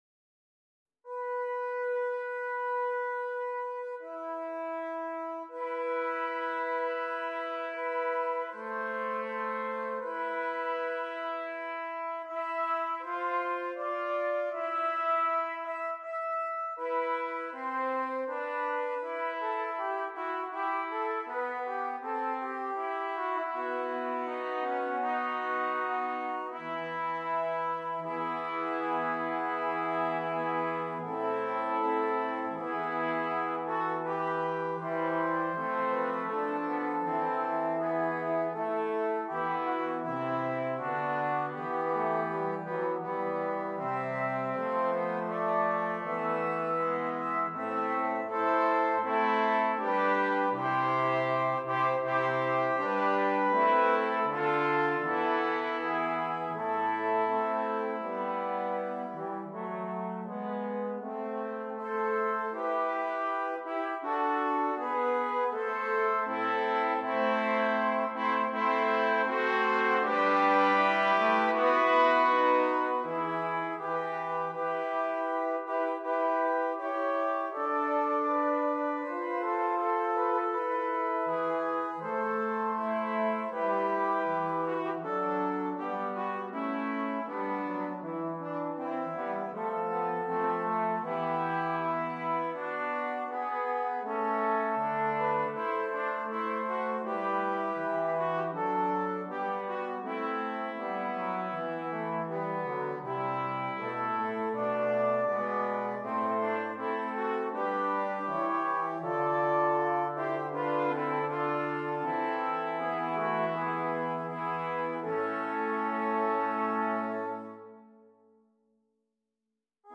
Brass Quartet
Alternate parts: Trombone (Horn)